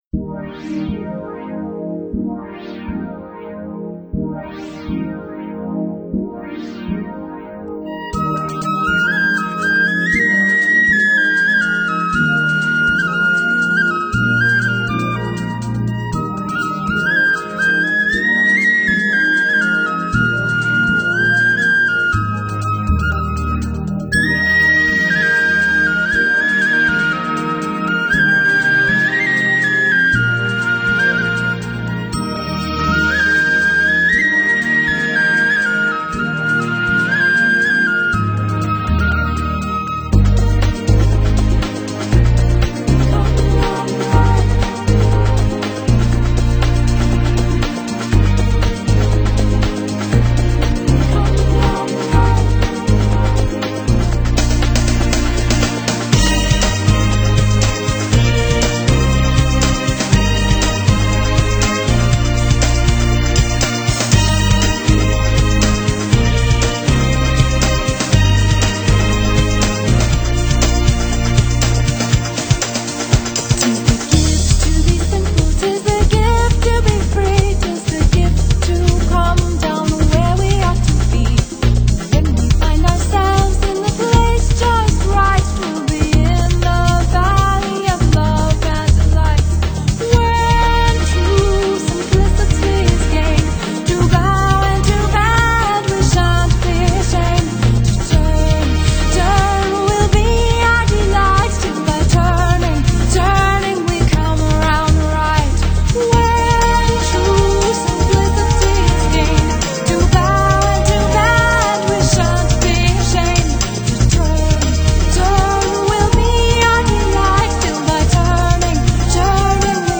这样的声音里，甜美的睡一个午觉，Banjo轻妙， Fiddle光滑，不必担心它们会吵着你。